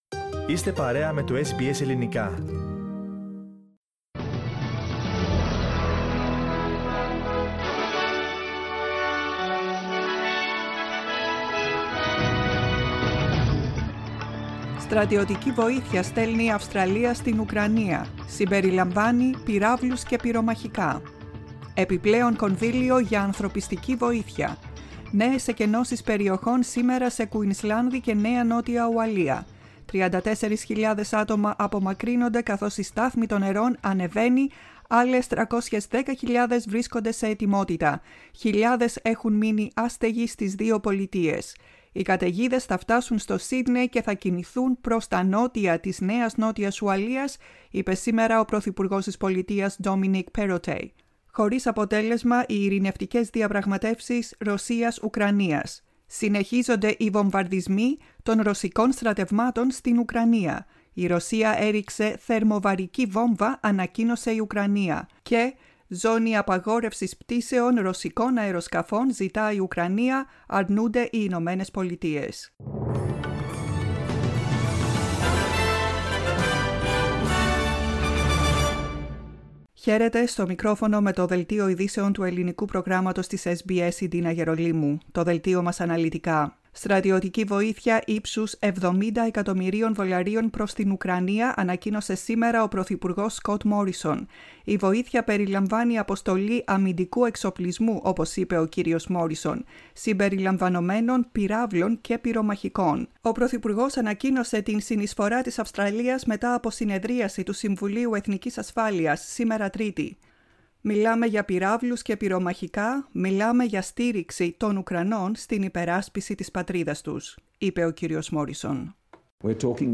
Listen to the main bulletin of the day from the Greek Program on Tuesday 1.03.22.